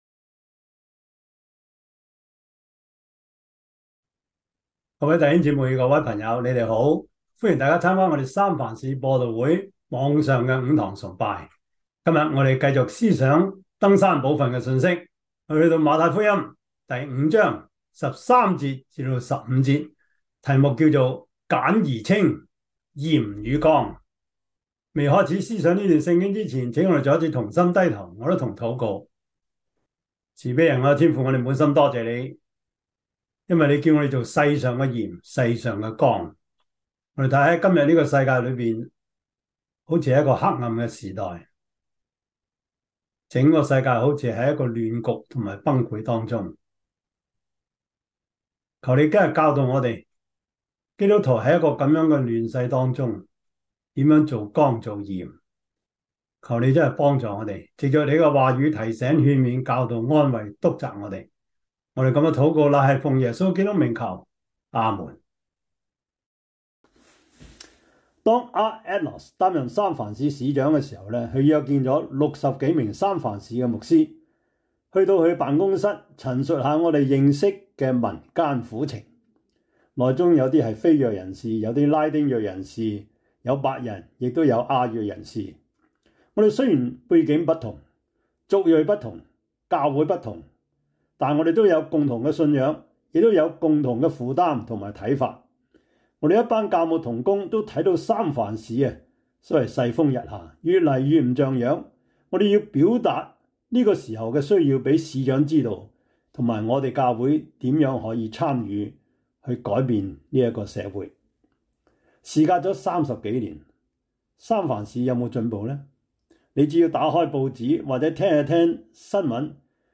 馬太福音 5:9-12 Service Type: 主日崇拜 馬太福音 5:9-12 Chinese Union Version